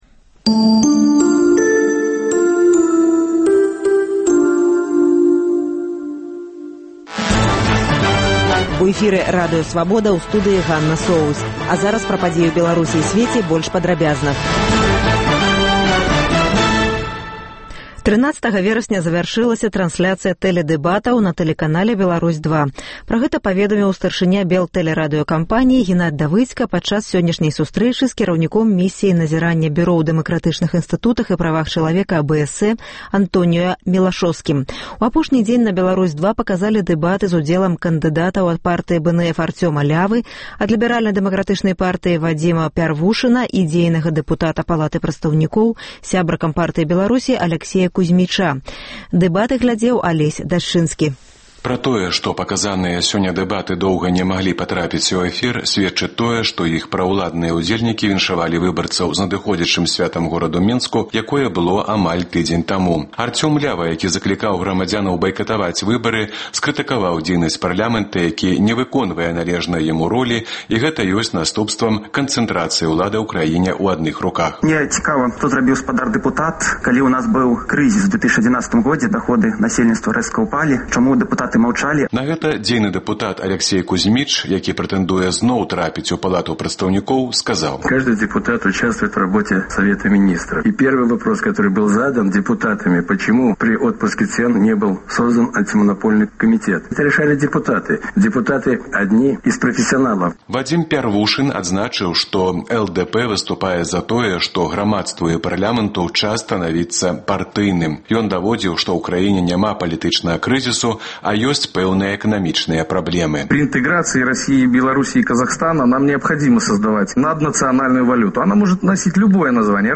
Падсумаваньне дня, бліц-аналіз, галасы людзей.